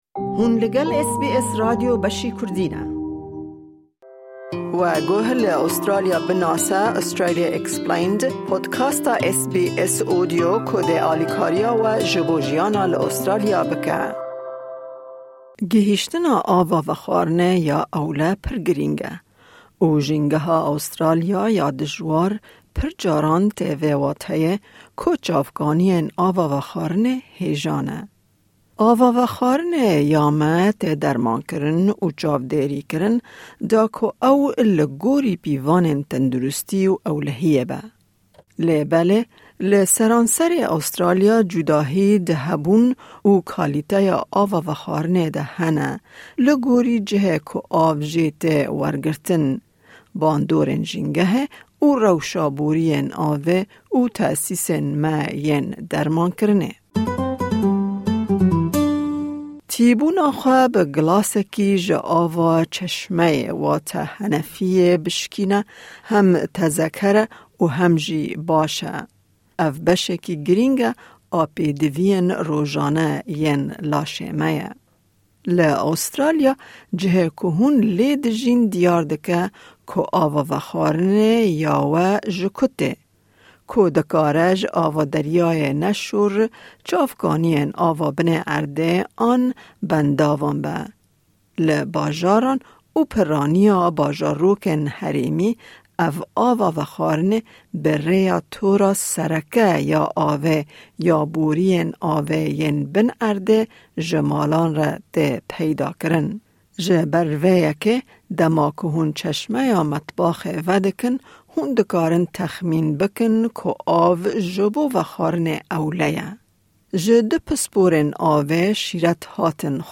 Ji ber cûdahiyên di hebûn û kalîteya ava vexwarinê de li seranserê welêt, em çawa bizanibin ka vexwarina wê ewle ye? Di vê beşî de pisporên avê bersîva pirsên me didin.